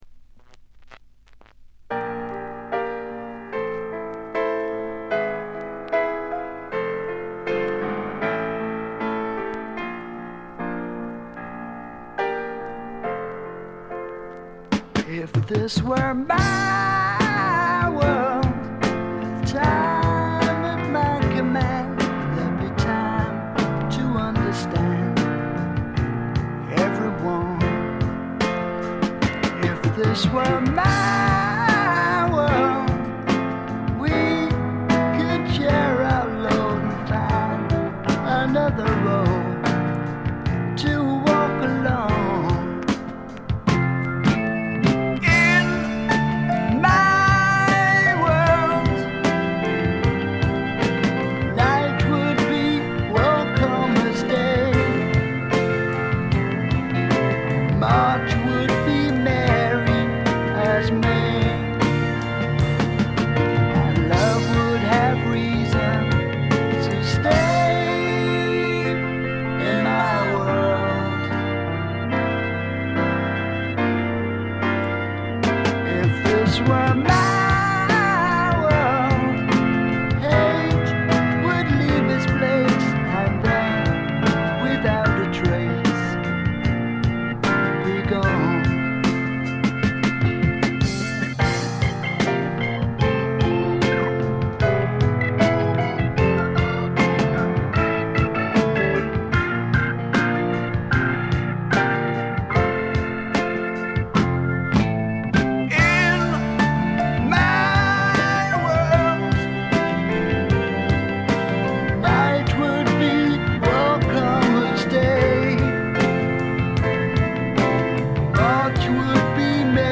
搾り出すような激渋のヴォーカルがスワンプ・サウンドにばっちりはまってたまりません。
試聴曲は現品からの取り込み音源です。